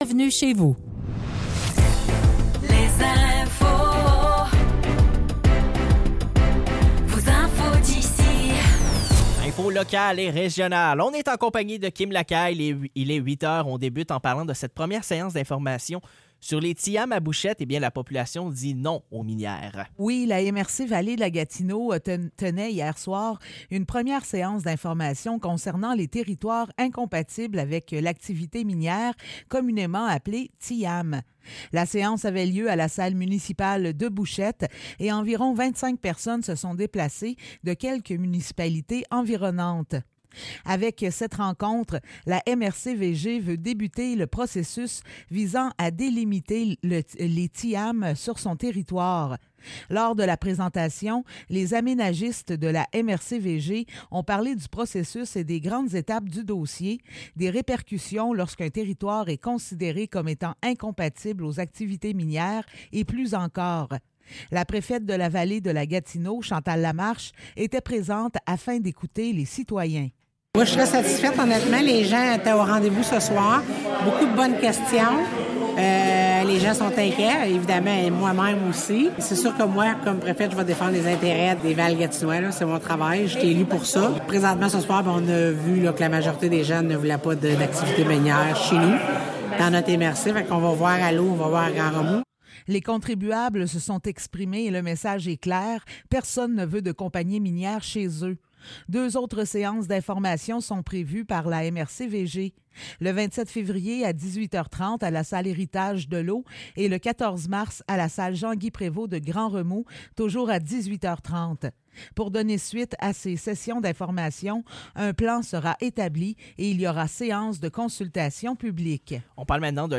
Nouvelles locales - 14 février 2024 - 8 h